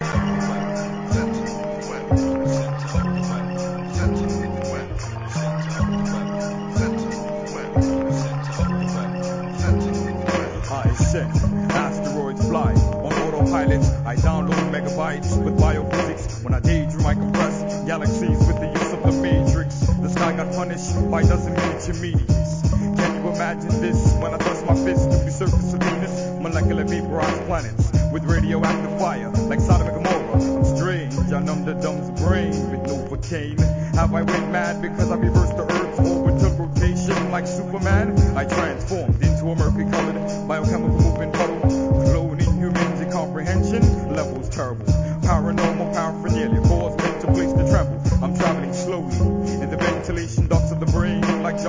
HIP HOP/R&B
DOPEアンダーグラウンド